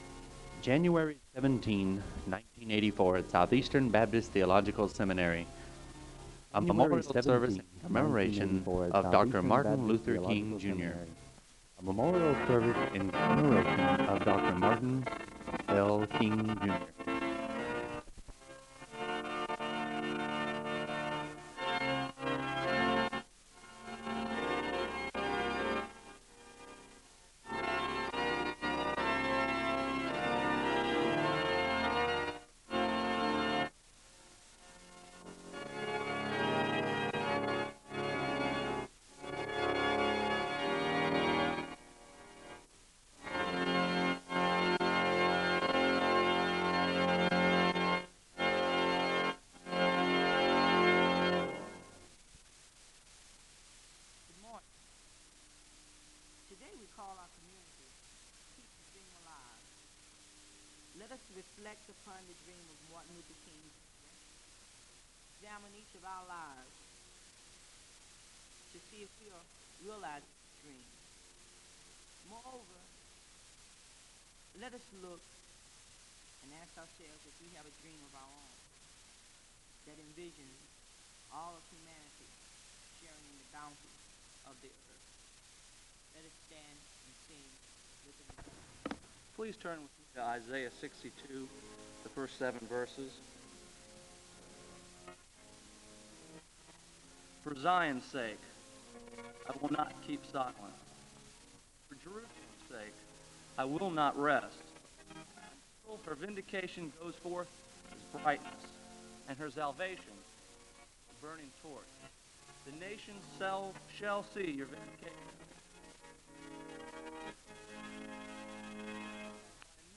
Audio quality is very poor.
The service begins with organ music (00:00-00:52).
The speaker delivers the Scripture reading from Isaiah 62:1-7, and a word of prayer is given (00:53-03:40).
The choir sings the anthem (04:12-07:01).
The audience is led in a song of worship (22:24-24:11).
Location Wake Forest (N.C.)